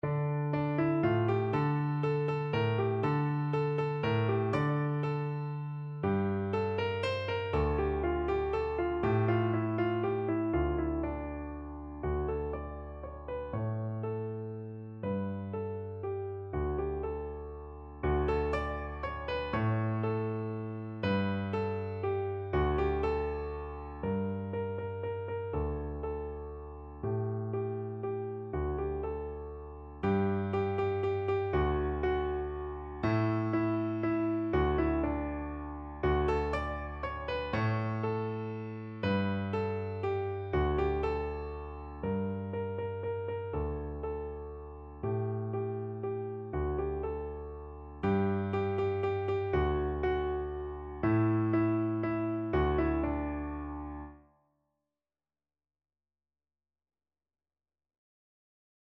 No parts available for this pieces as it is for solo piano.
3/4 (View more 3/4 Music)
= 60 Allegro (View more music marked Allegro)
Piano  (View more Easy Piano Music)
World (View more World Piano Music)